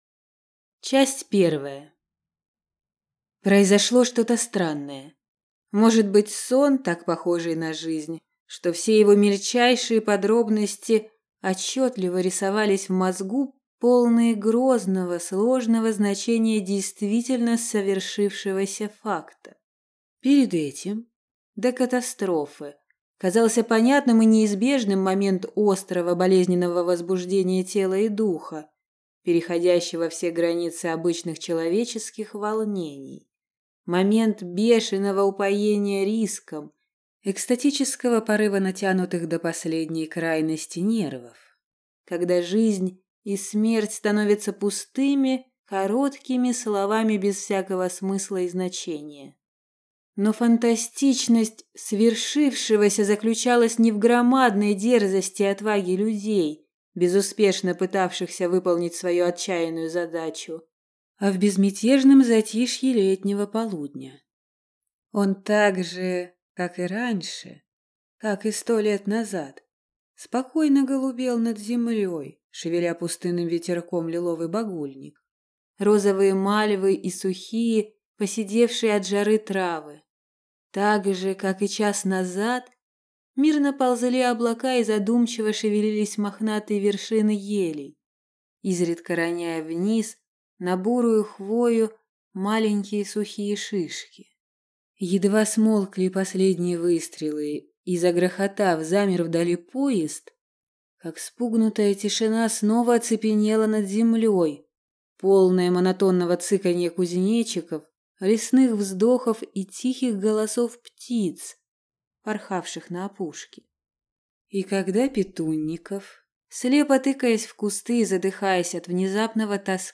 Аудиокнига Телеграфист из Медянского бора | Библиотека аудиокниг